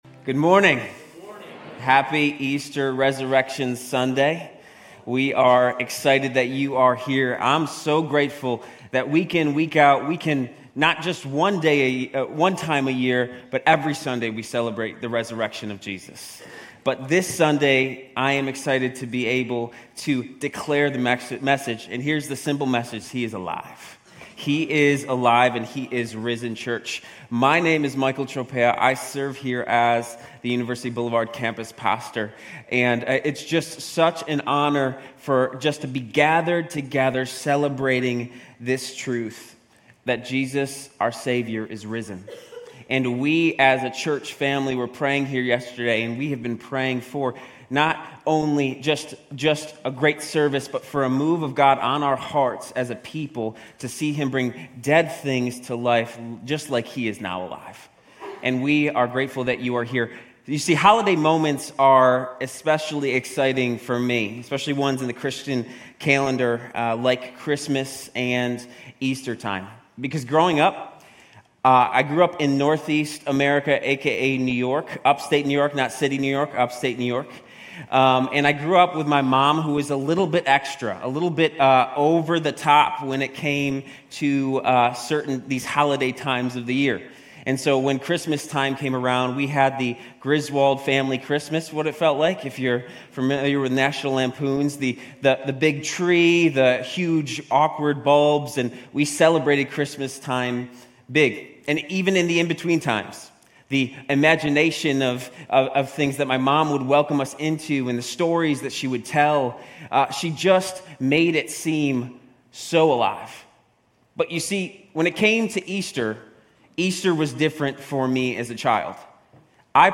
Grace Community Church University Blvd Campus Sermons Easter Sunday Apr 01 2024 | 00:40:27 1x 00:00 / 00:40:27 Subscribe Share RSS Feed Share Link Embed